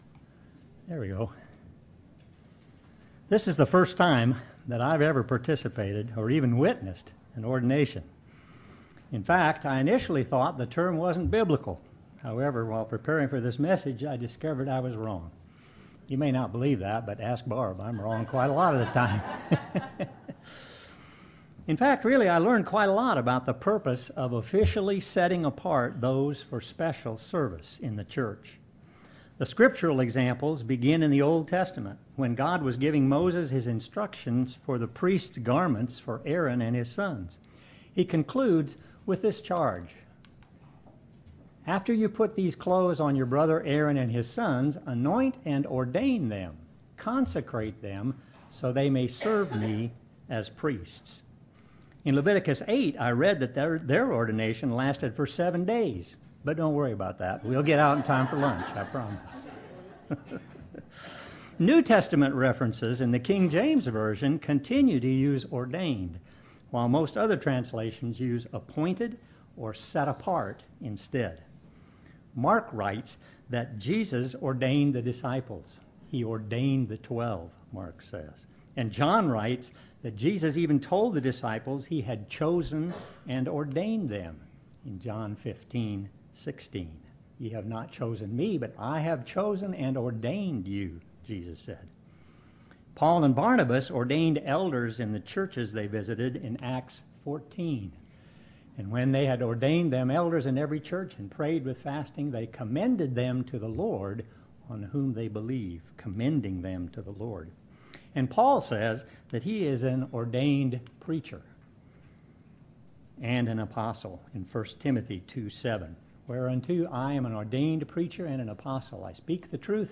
Special Service - Ordination